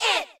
okItsOngirls3.ogg